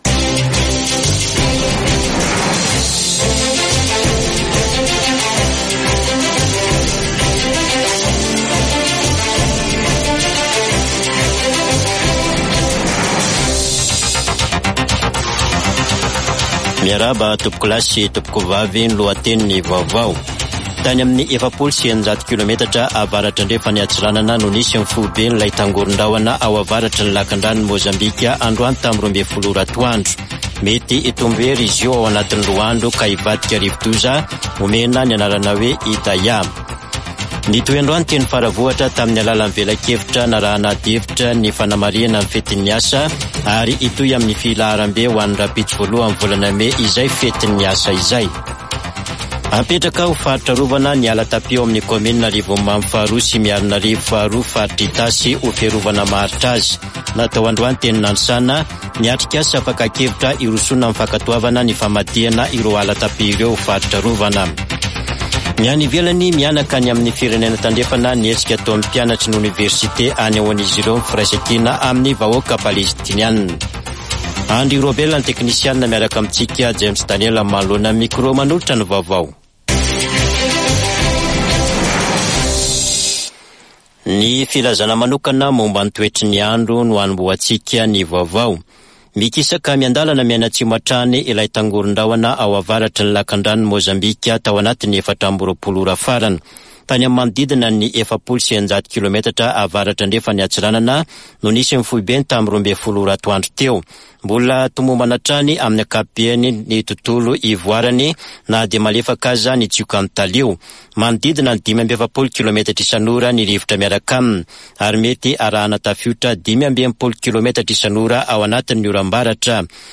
[Vaovao hariva] Talata 30 aprily 2024